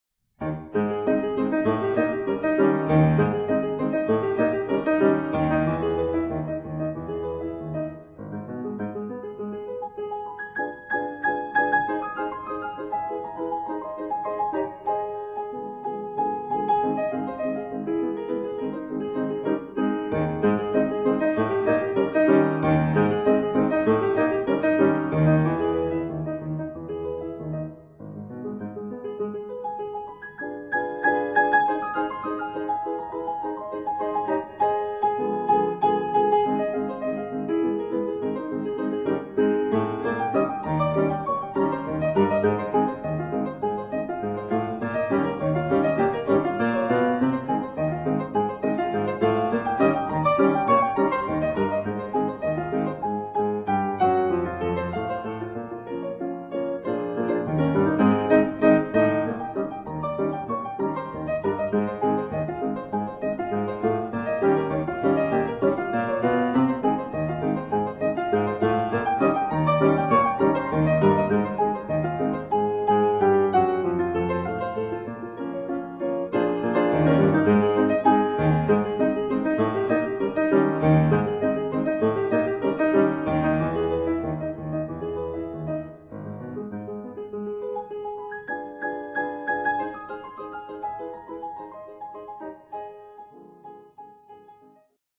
Scott Joplin’s “Maple Leaf Rag,” ragtime’s biggest hit.